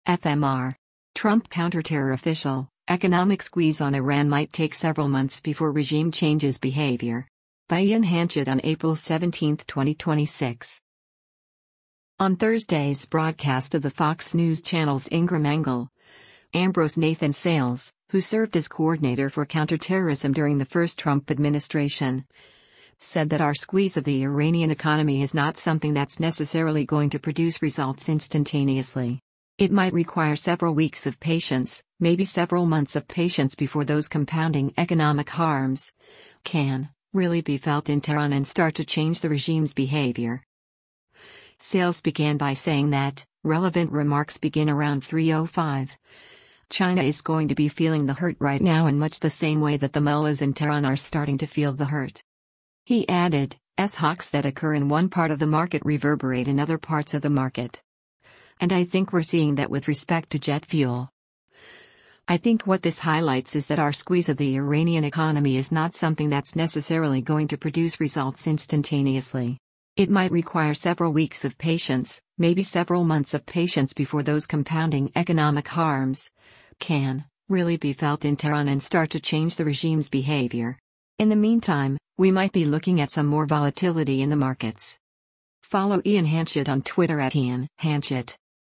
On Thursday’s broadcast of the Fox News Channel’s “Ingraham Angle,” Amb. Nathan Sales, who served as Coordinator for Counterterrorism during the first Trump administration, said that “our squeeze of the Iranian economy is not something that’s necessarily going to produce results instantaneously.